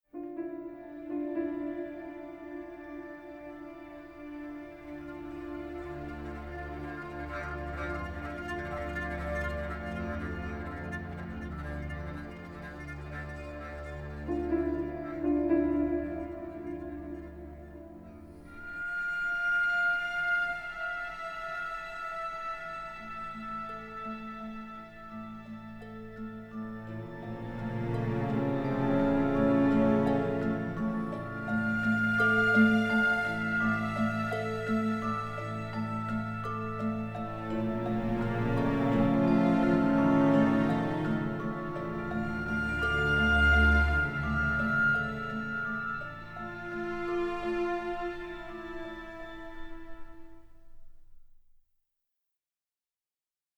Genre : Soundtrack